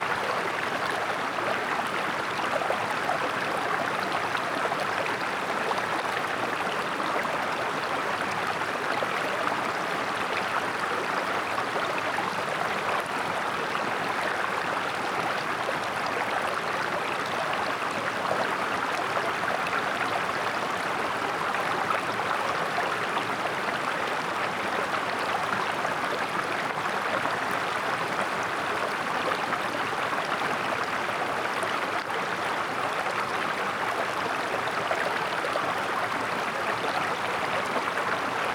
Watr Flow Subtle 03.wav